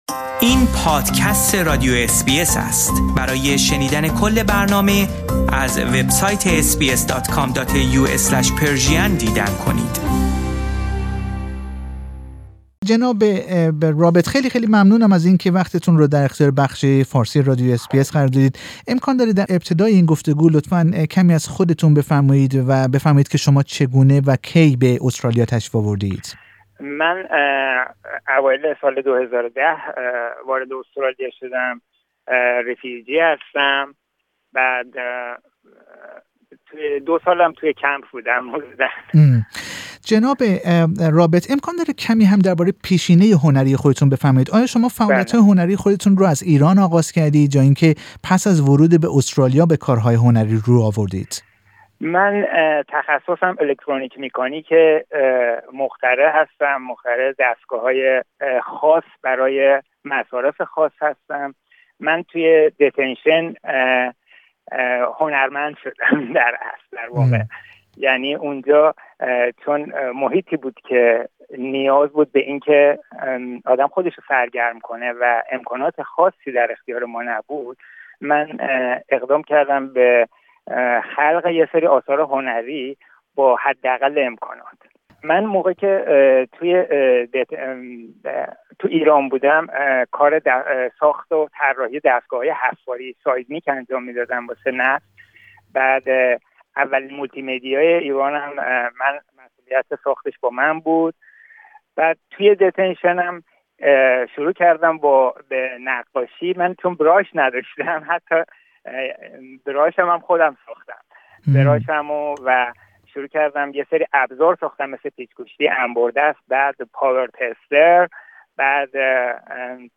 به همین منظور گفتگویی داشتیم